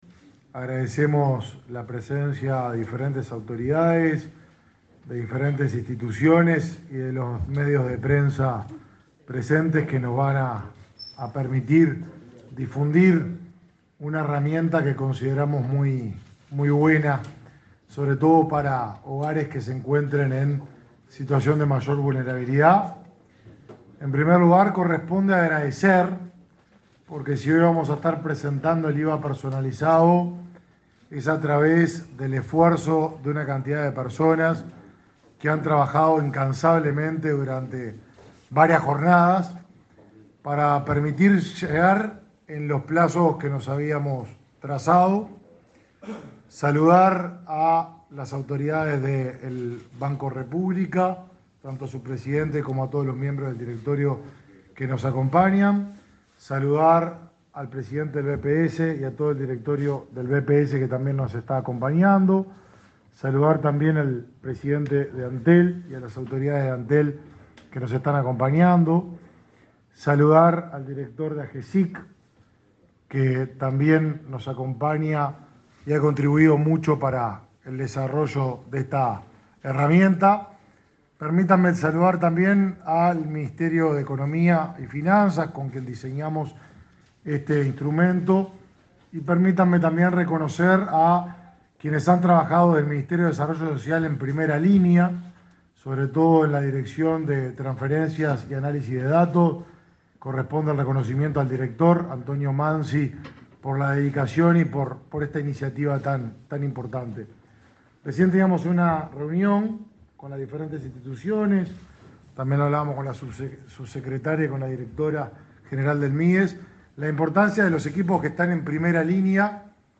Palabras del ministro de Desarrollo Social, Martín lema
El ministro de Desarrollo Social, Martín Lema, encabezó una conferencia realizada para presentar el impuesto al valor agregado (IVA) personalizado,